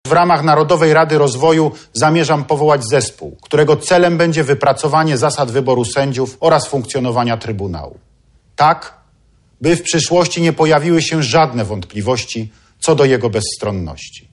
Prezydent RP wieczorem wygłosił orędzie do narodu
orędzie prezydenta
– mówi Prezydent Andrzej Duda.
orędzie-prezydenta.mp3